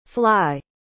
fly.mp3